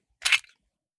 fps_project_1/45 ACP 1911 Pistol - Magazine Unload 001.wav at 3e46060a70848c52f8541c7d1ccb36af7950df5e